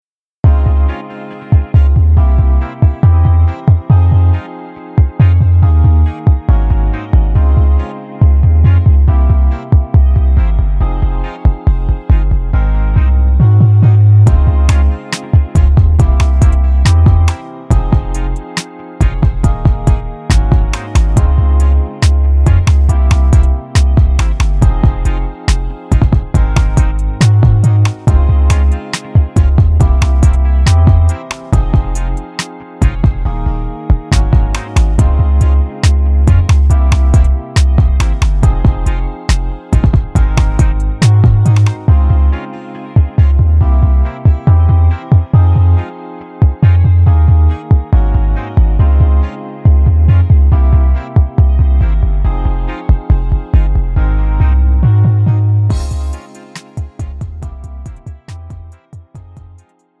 음정 원키
장르 pop 구분 Pro MR